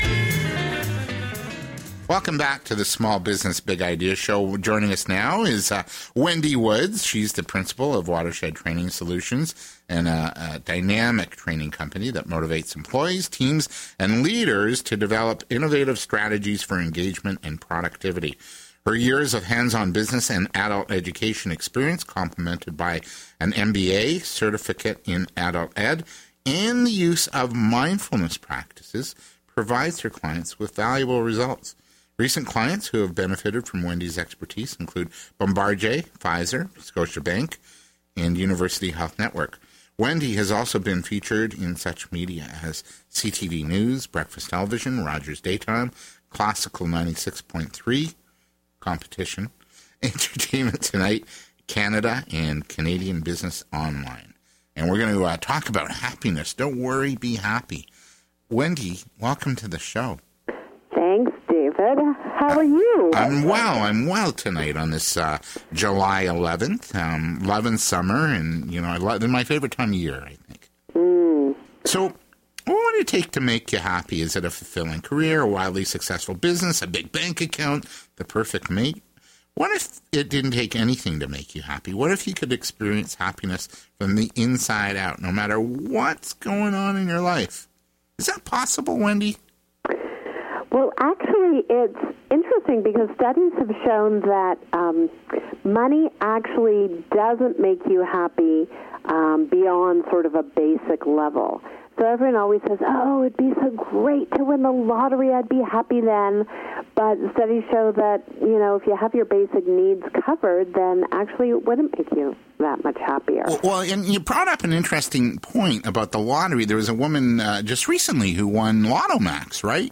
Happiness interview – Jul. 2011